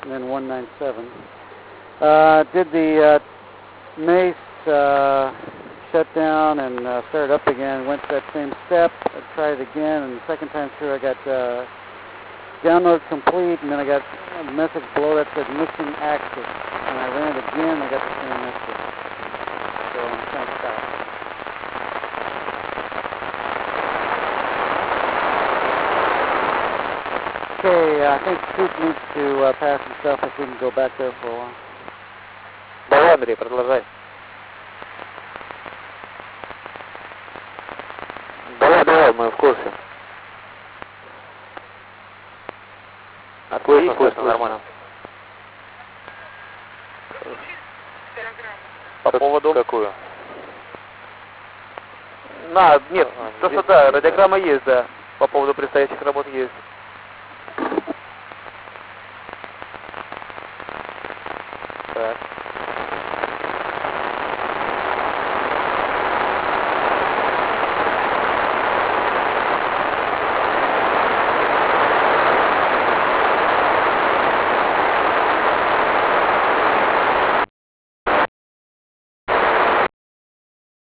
МКС. Фрагмент сеанса связи: в эфире американские и русские космонавты.
Начало » Записи » Записи радиопереговоров - МКС, спутники, наземные станции